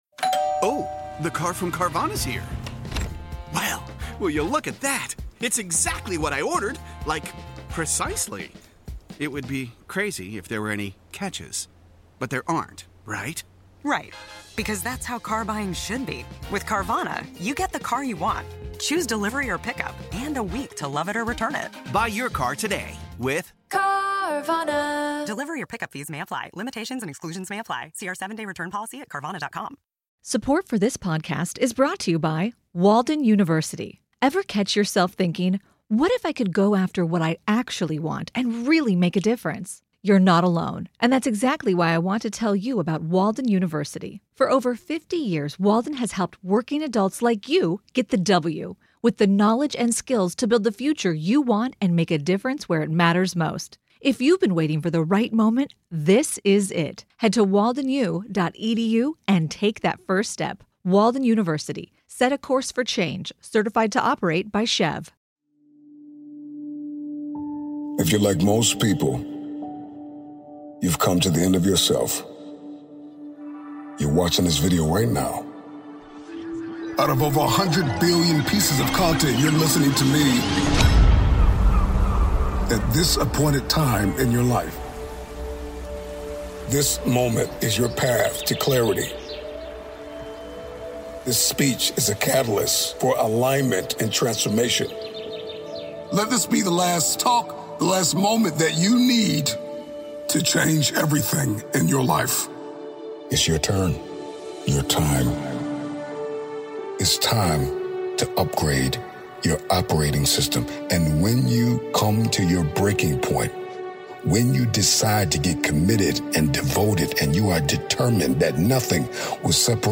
Best Motivational Speeches Ever